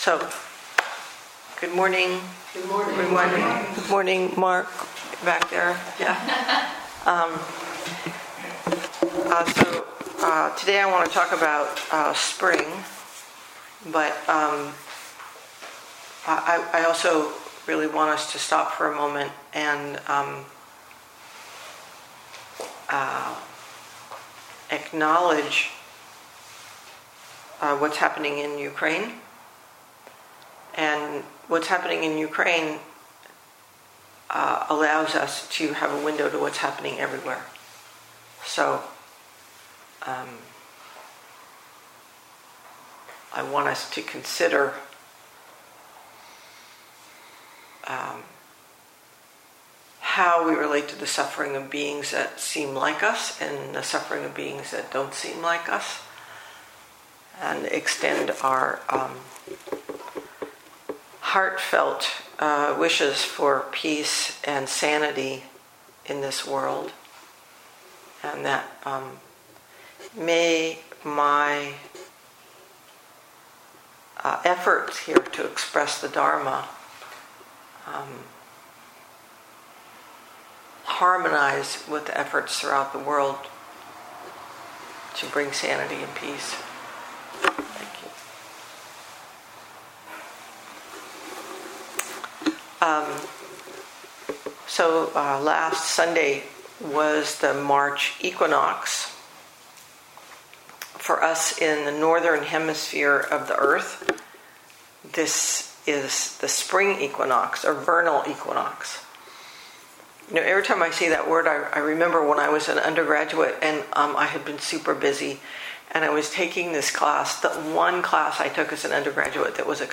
2022 in Dharma Talks